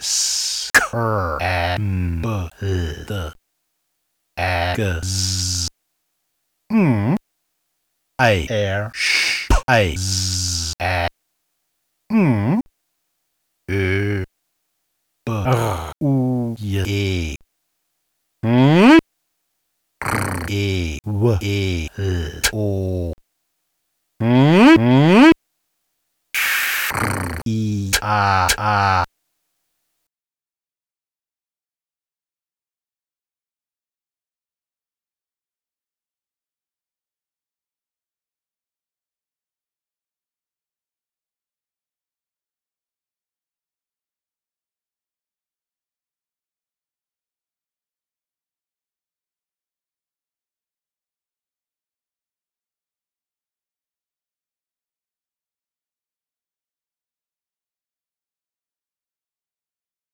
phonetic typewriter How do you like your egg?
sehr präzise und souverän aufgebauter beitrag - besonders gefällt der entschlossene und dabei subtile einsatz der extravagentern klangpartikel, die phont bereitstellt: diese speziellen töne bieten zwar moeglichkeiten der steigerung aber geraten dabei leicht ausser kontrolle. hier sind sie stimmig verwendet. ein sehr kompakter, dramatischer und dabei entspannter beitrag.